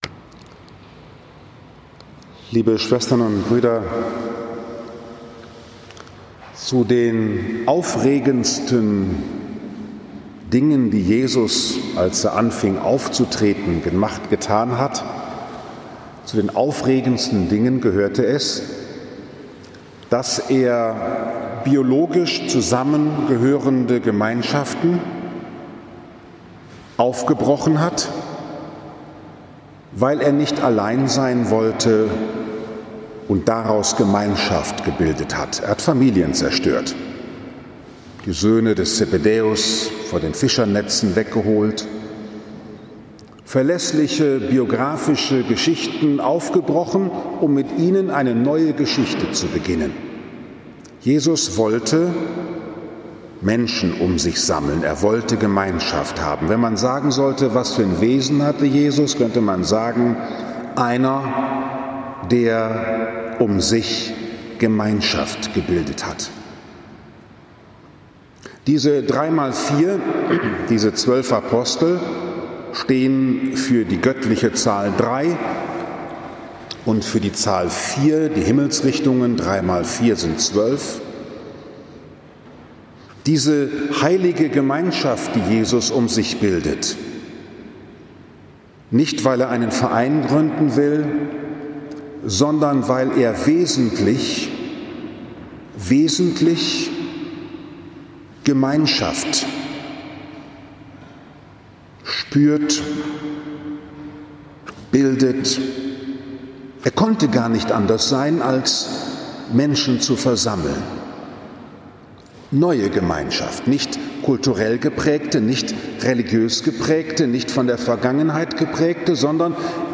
Gesammelt durch Christus im Heiligen Geist zum Vater hin Das Wesen Jesu ist das Wesen der Christen: Die Einheit der Menschheitsfamilie zu fördern über Grenzen von Rasse, Religion und Nation hinweg. 7. Juni 2020, 11 Uhr Liebfrauenkirche Frankfurt am Main, Dreifaltigkeitssonntag LJ A